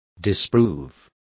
Complete with pronunciation of the translation of disproved.